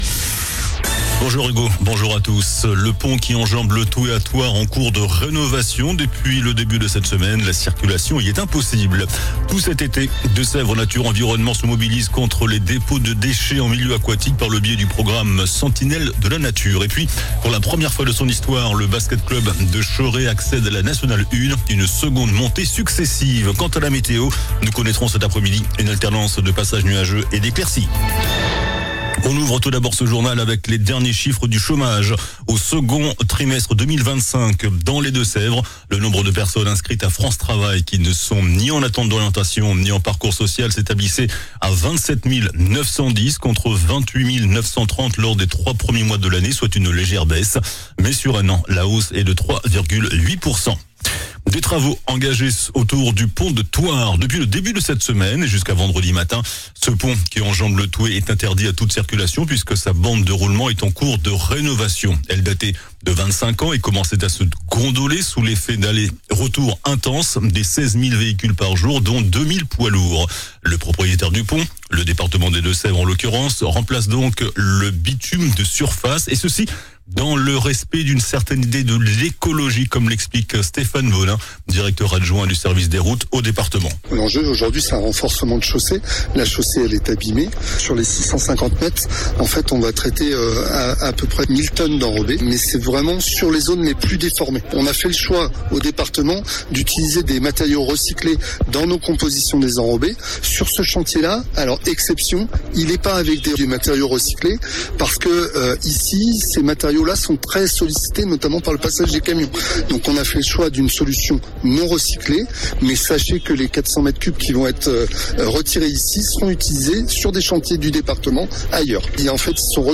JOURNAL DU MERCREDI 30 JUILLET ( MIDI )